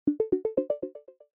SMS Alert
All new iOS 17 notification sounds.